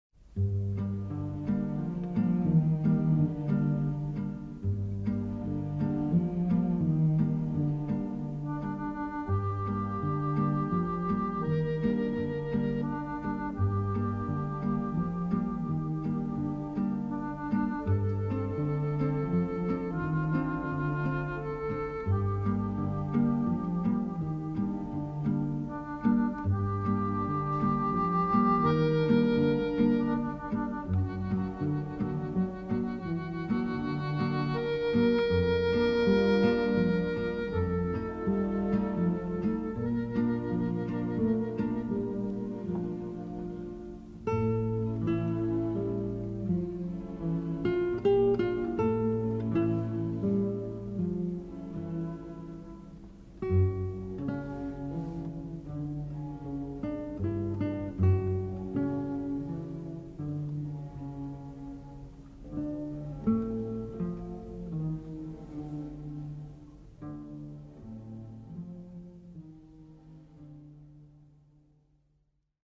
beautiful, sweeping soundtrack
from the scoring sessions
lush, emotional music